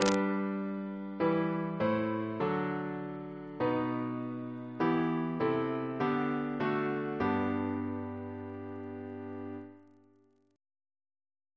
Single chant in G Composer: Anonymous Reference psalters: PP/SNCB: 9